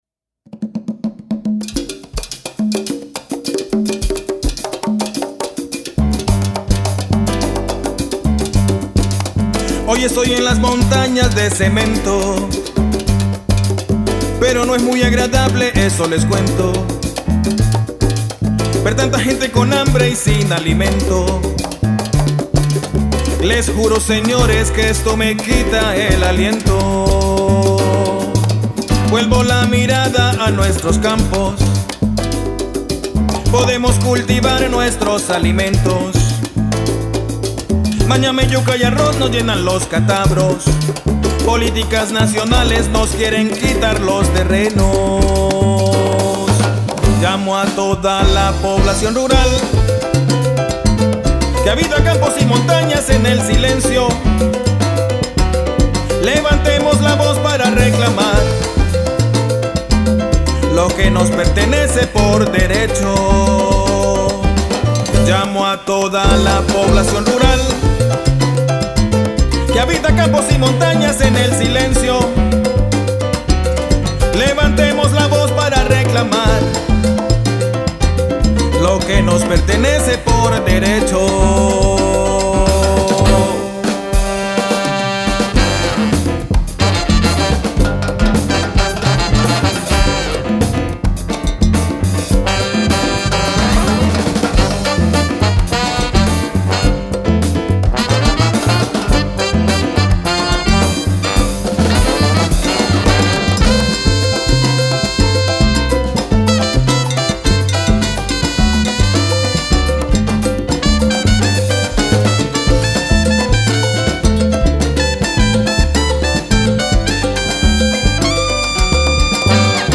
Canción
congas, bongoes, quinto, campana.
piano.
bajo.
timbal, batería.
saxofón.
trompeta.
trombón.